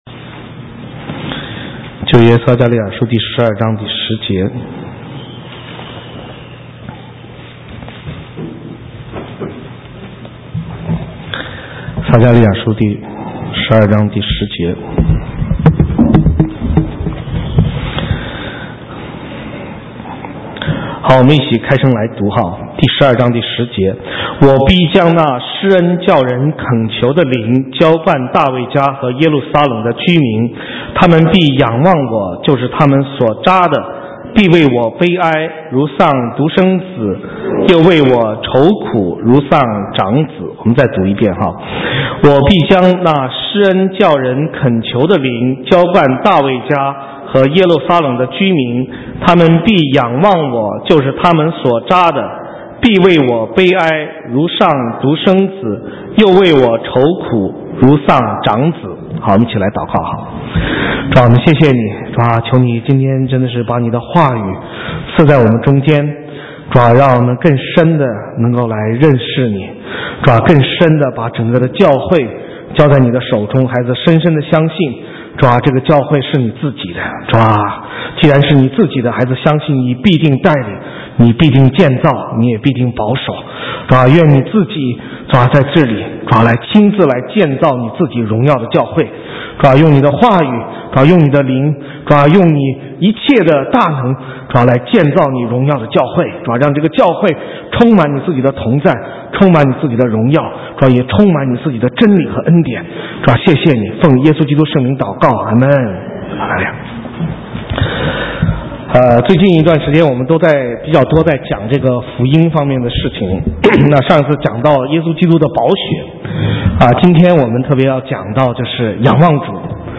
神州宣教--讲道录音 浏览：仰望主 (2011-06-12)